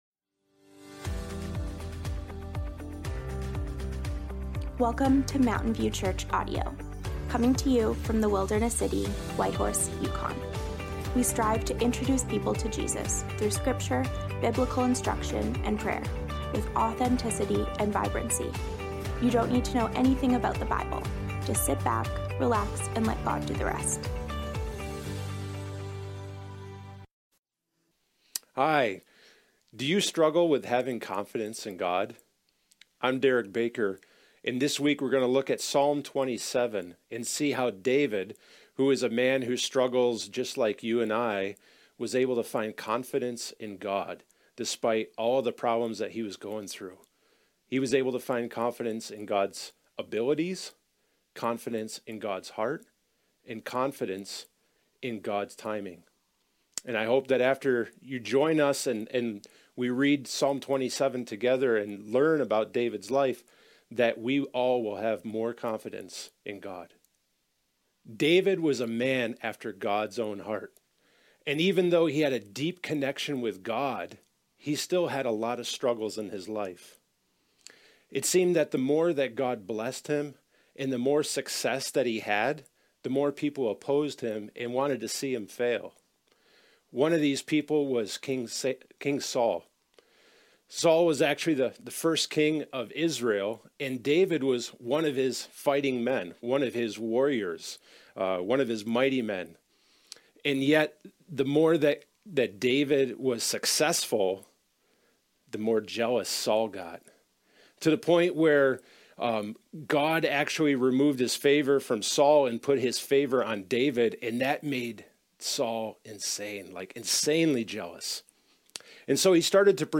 Have Confidence in God (Psalms, Ep. 5 - Sermon Audio)